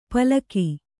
♪ palaki